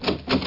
Doorknob Sound Effect
Download a high-quality doorknob sound effect.
doorknob.mp3